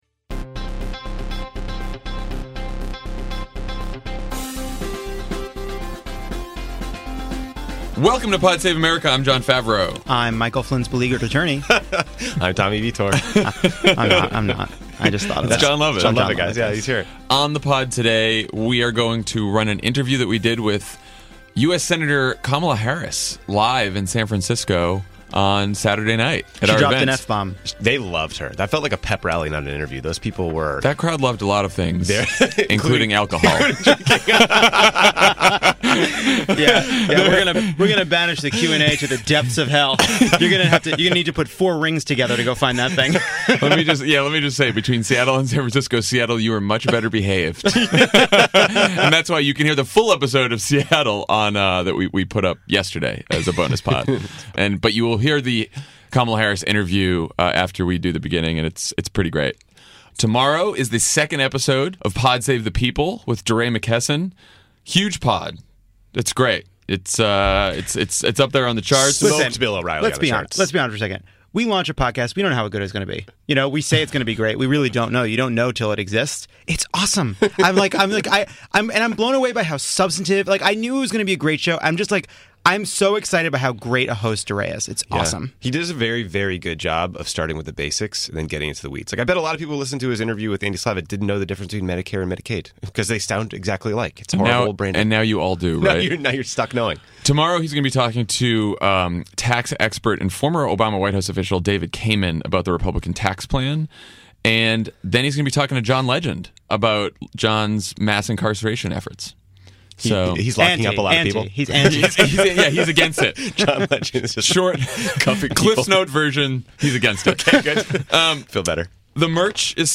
French elections, Sally Yates' testimony, Kushner family corruption, the latest on health care, and our interview with Senator Kamala Harris recorded live in front of a rowdy San Fransisco crowd.